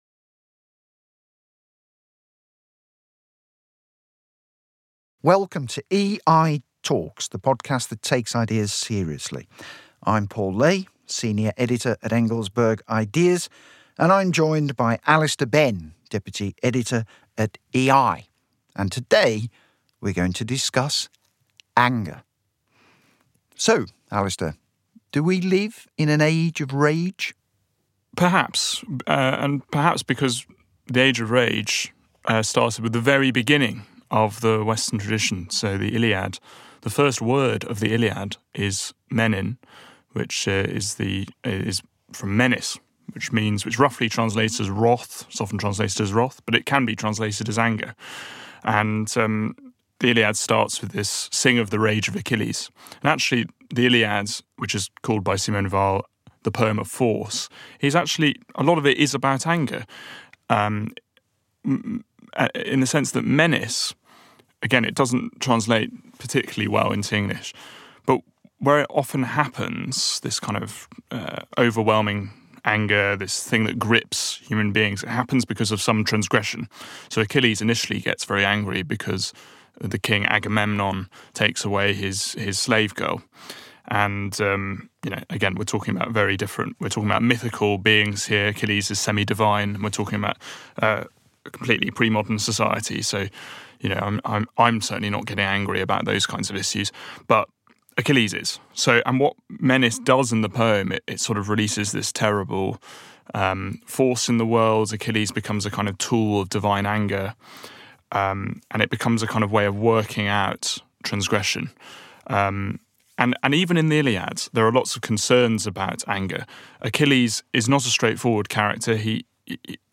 calmly discuss the uses and misuses of anger.